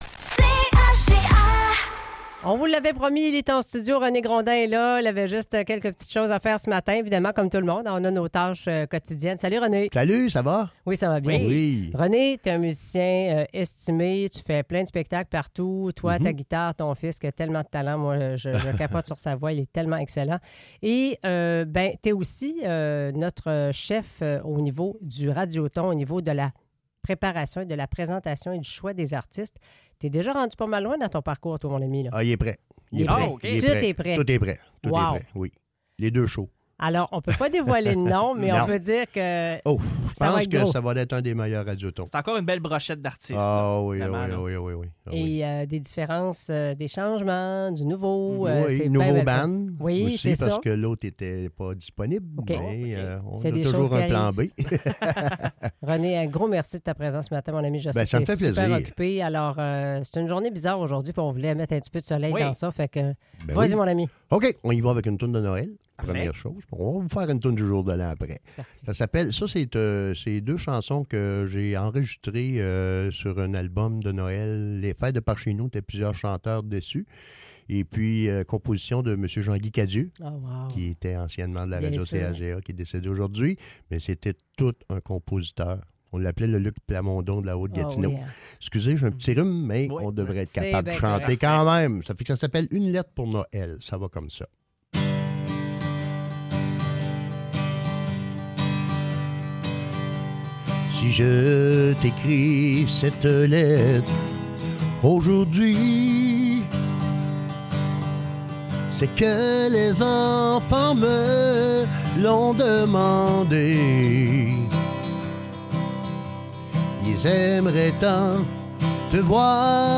Musique en studio